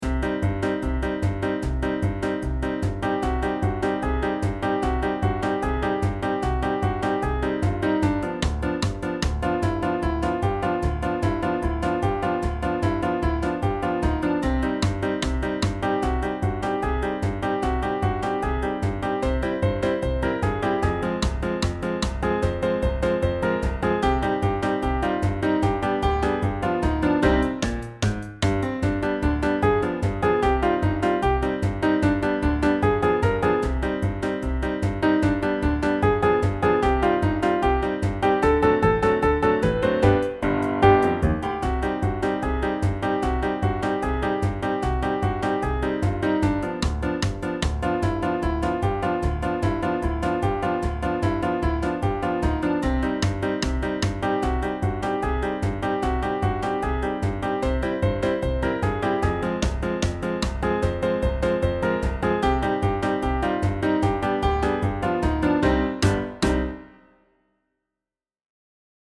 bijlage-4-muziek-uit-oude-rommel-karaoke-versie.mp3